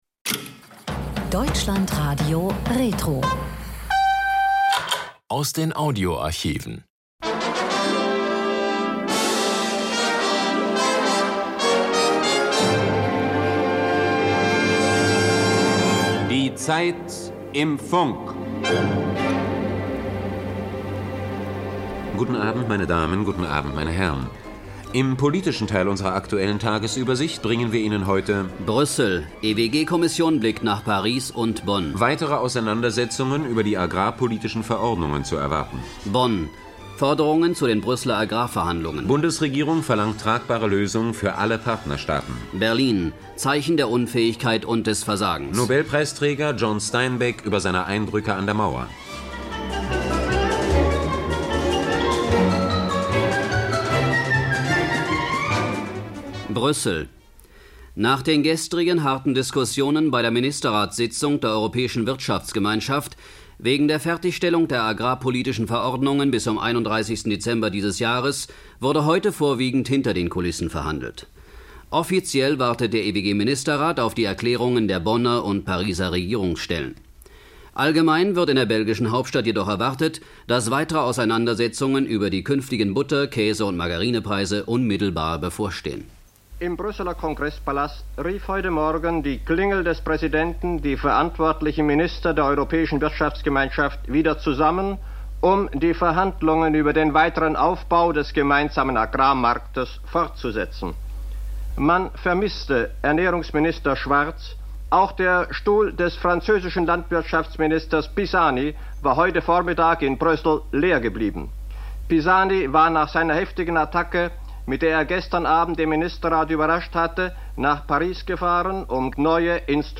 In "Valentinstag" konfrontiert Richard Ford seinen mit ihm gealterten Helden mit dessen todkrankem Sohn. Ein Gespräch über Kunst, Leben und US-Politik.